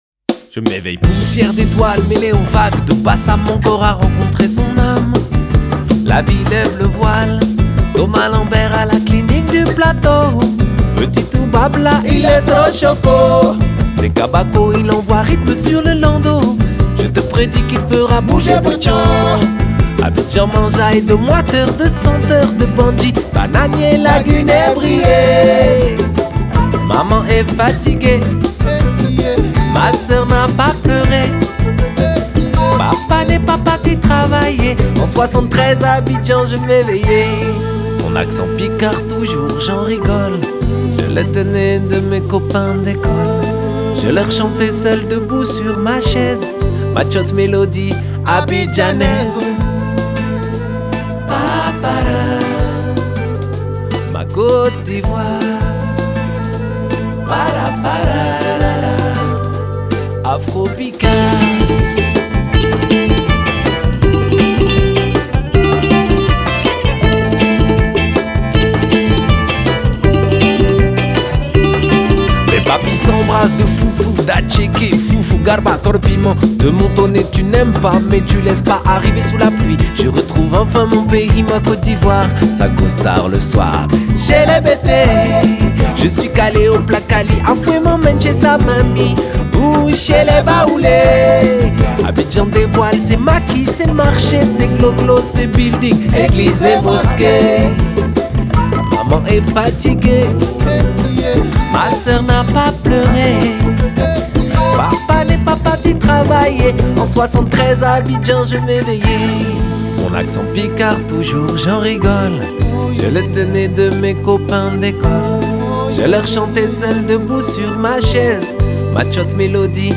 Genre: World, Folk